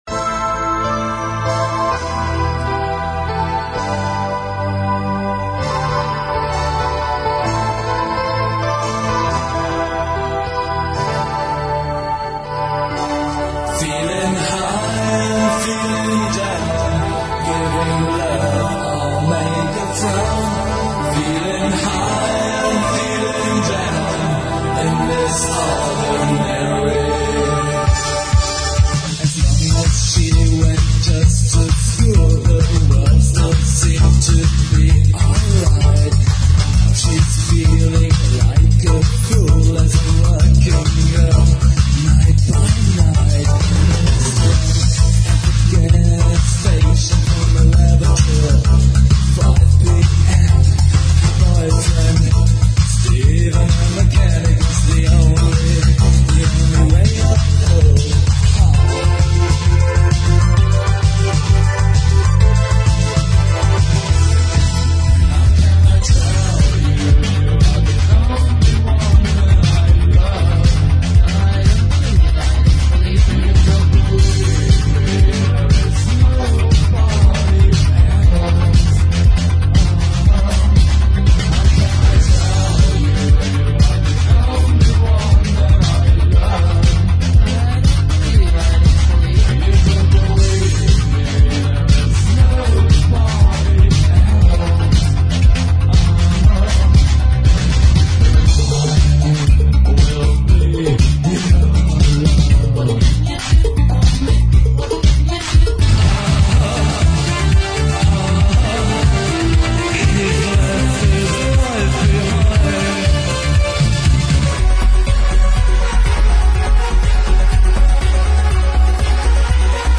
This is low quality file for prelisten only.